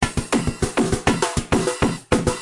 描述：以100bpm的速度进行直8音鼓的填充
Tag: 阿门 断线 填充 工业